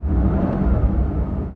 CosmicRageSounds / ogg / general / cars / rev3.ogg